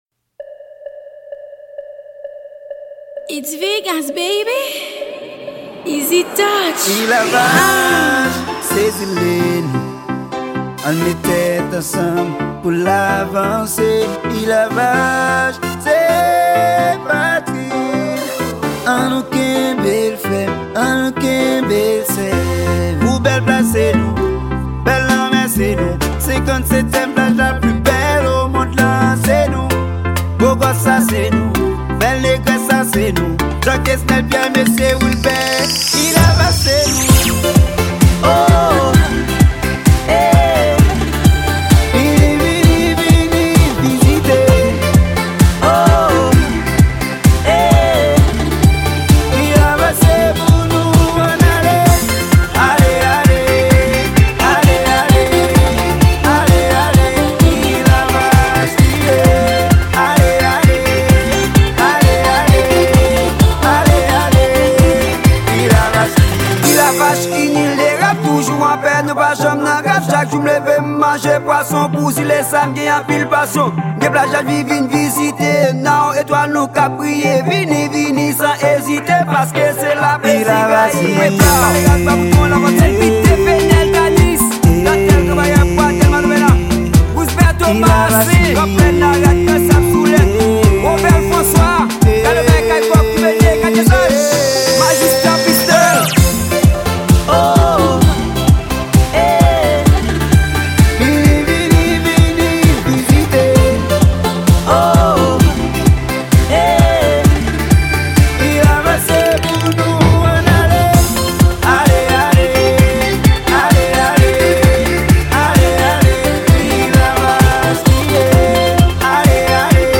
Genre: rrap.